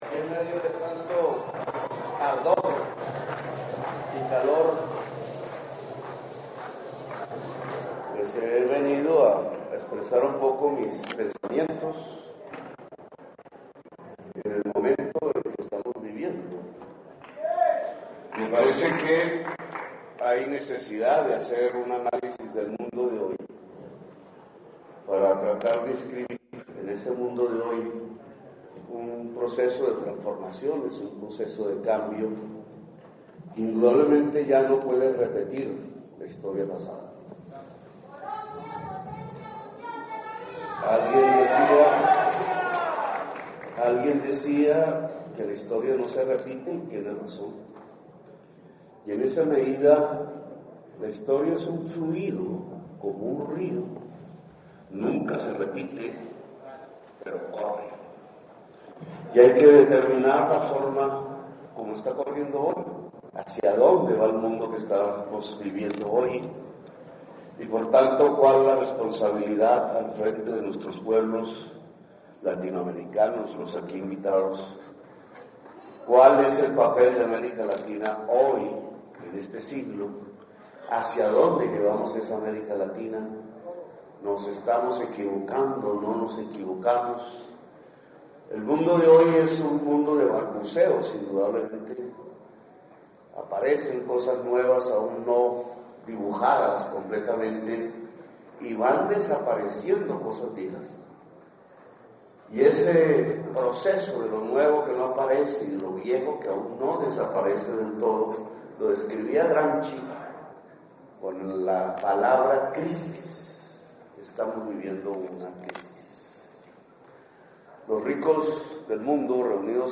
Intervencion-del-Presidente-Gustavo-Petro-en-la-‘Cumbre-de-los-Pueblos-en-Bruselas-julio-17.mp3